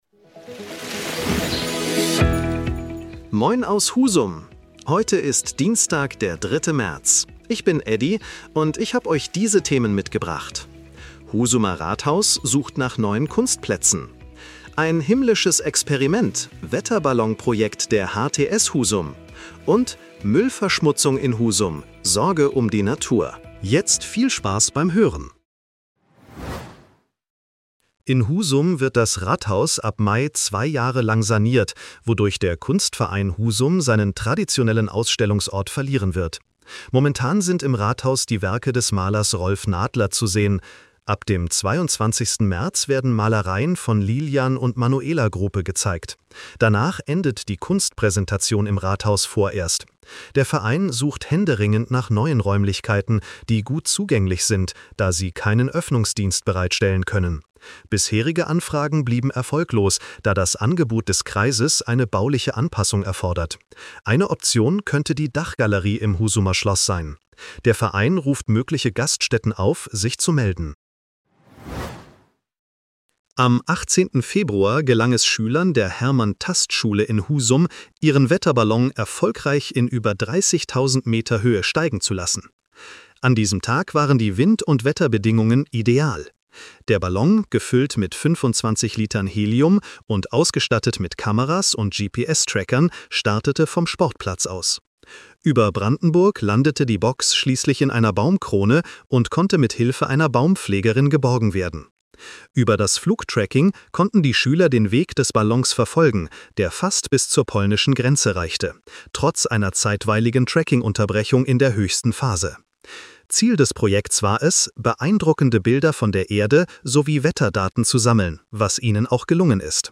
In unserem regionalen Nachrichten-Podcast